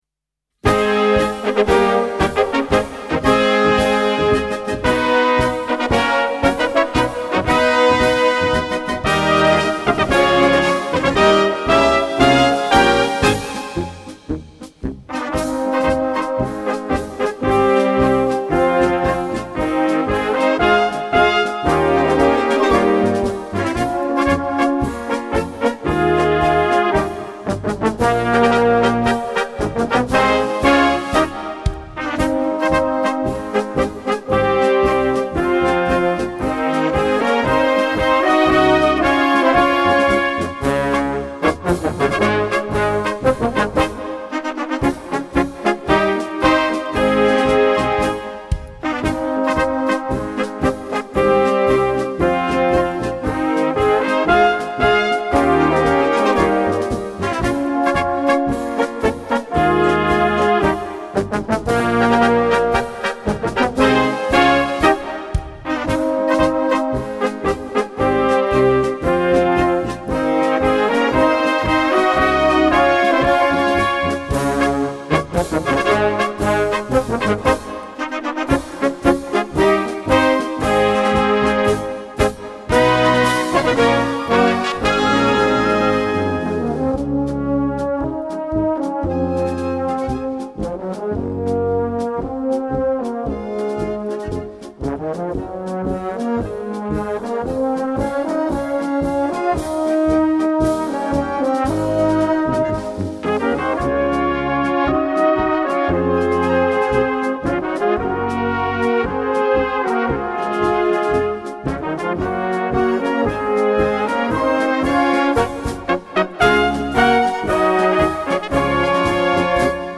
Konzertmärsche